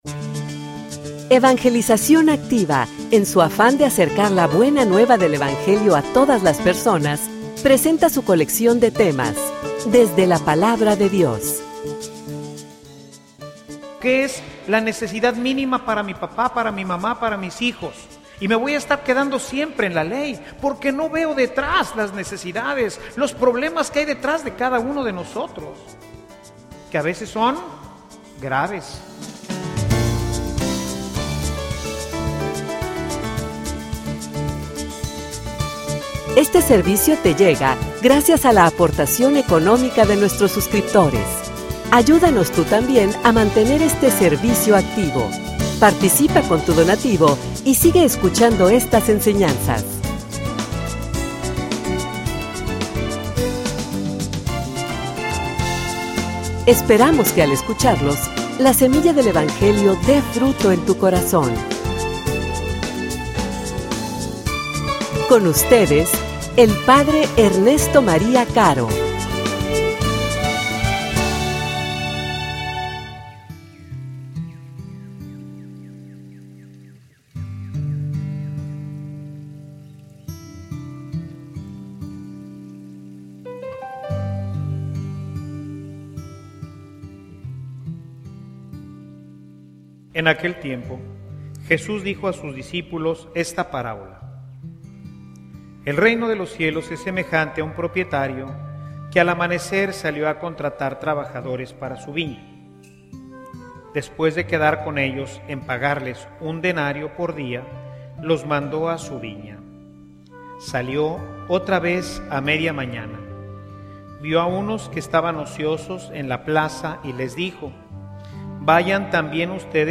homilia_De_la_justicia_a_la_generosidad.mp3